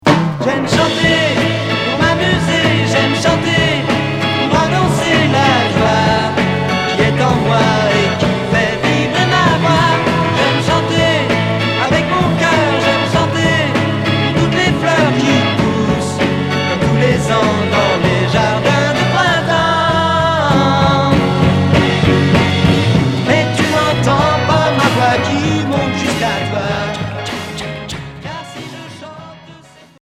Pop psychédélique